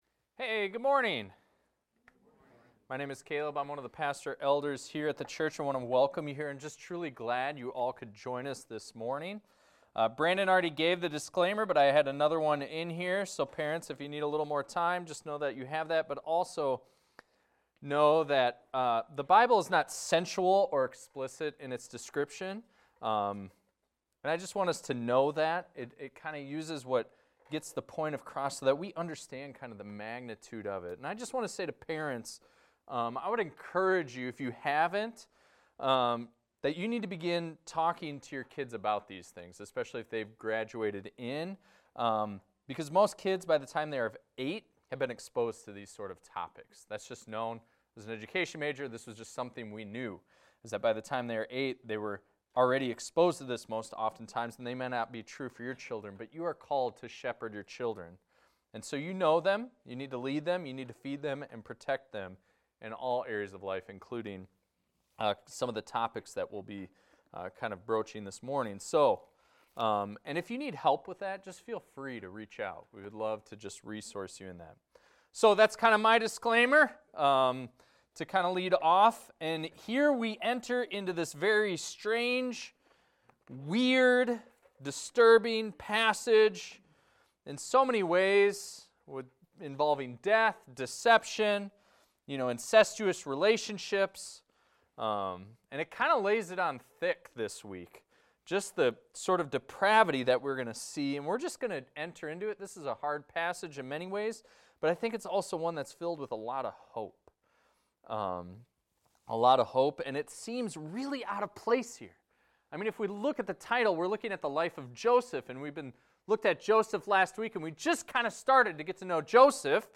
This is a recording of a sermon titled, "Judah and Tamar."